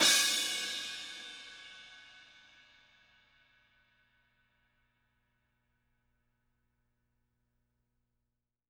R_B Crash B 01 - Close.wav